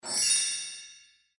audio: Converted sound effects